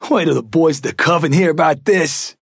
Trapper voice line - Wail 'til the boys in the Coven hear about this!